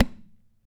CHARANGNOIAP.wav